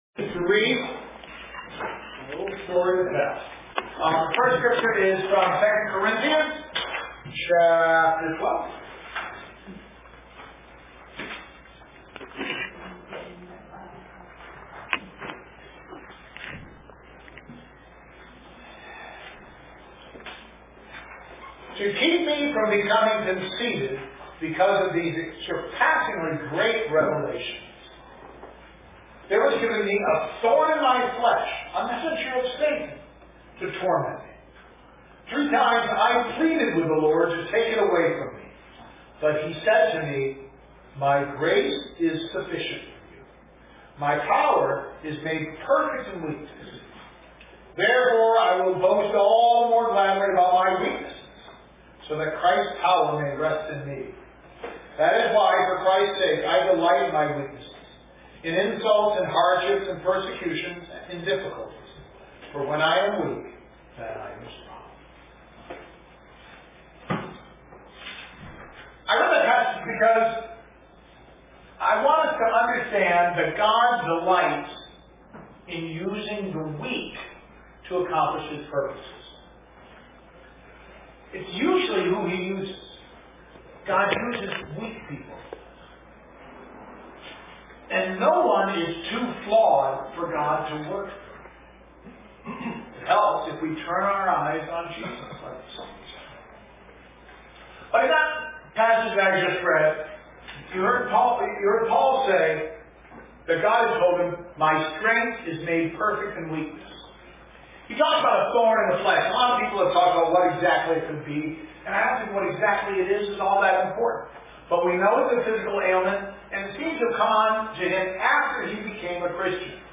Sermon-6-26-19.mp3